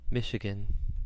Ääntäminen
Ääntäminen UK : IPA : /ˈmɪʃ.ɪ.ɡən/ US : IPA : /ˈmɪʃ.ɪ.ɡən/ US UK : IPA : /ˈmɪʃɪɡn̩/ IPA : US : IPA : [ˈmɪʃɪ̈ɡɪ̈n] IPA : [ˈmɪʃɪ̈ɡn̩] Lyhenteet ja supistumat Mich.